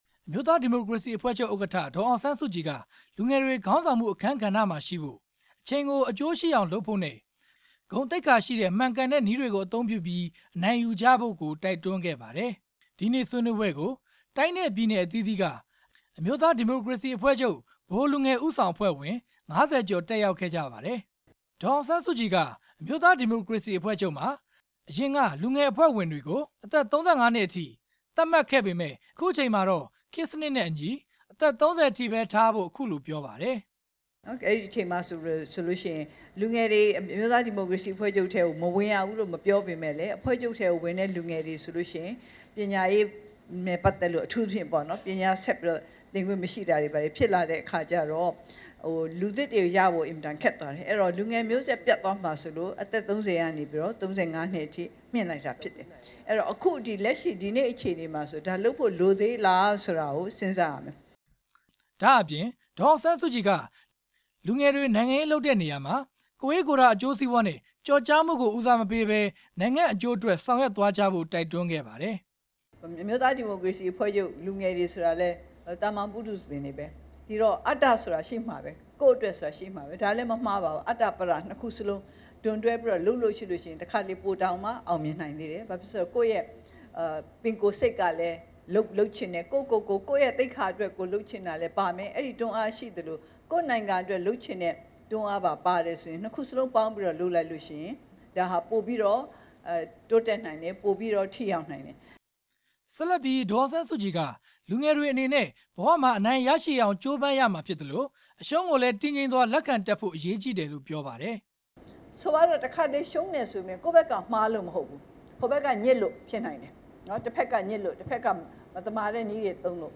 ဒေါ်အောင်ဆန်းစုကြည် ပြောကြားချက်များ
တောင်ဝင်နှင်းဆီခန်းမမှာကျင်းပတဲ့ အမျိုးသား ဒီမိုကရေစီအဖွဲ့ချုပ် ပထမအကြိမ် နိုင်ငံလုံးဆိုင်ရာ ဗဟိုလူငယ်ဦးဆောင် အဖွဲ့အစည်းအဝေးမှာ လူငယ်တွေကို မှန်ကန်တဲ့ အနိုင်ယူနည်းကို တန်ဖိုးထားကြဖို့ ဒေါ်အောင်ဆန်းစုကြည်က အခုလို တိုက်တွန်းသွားတာပါ။